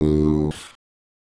Worms speechbanks
Ooff1.wav